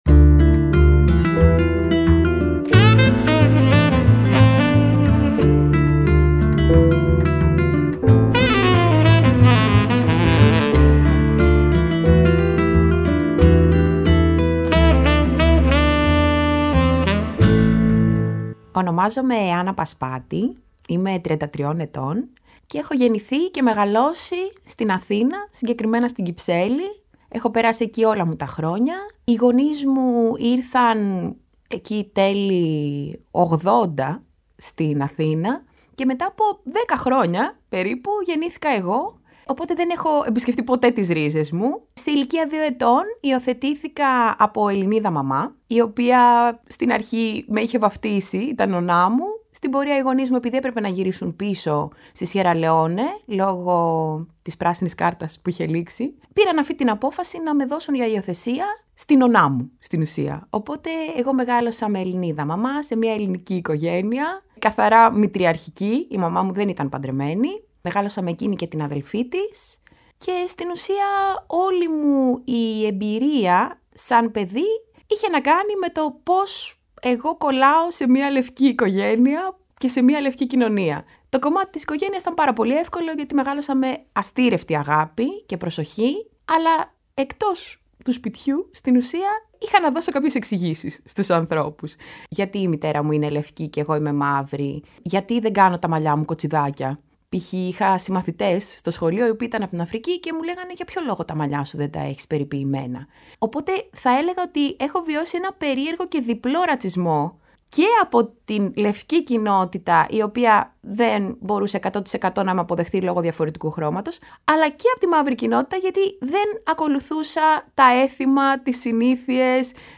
Στο πλαίσιο της συνεργασίας του Μουσείου Μπενάκη με την ΕΡΤ και το Κosmos 93,6, ηχογραφήθηκε, ειδικά για την έκθεση, μια σειρά προσωπικών αφηγήσεων μερικών από τους μουσικούς που συμμετέχουν στο ηχοτοπίο ΗΧΗΤΙΚΕΣ ΔΙΑΣΤΑΣΕΙΣ ΑΦΡΙΚΑΝΙΚΗΣ ΔΙΑΣΠΟΡΑΣ.